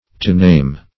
Meaning of to-name. to-name synonyms, pronunciation, spelling and more from Free Dictionary.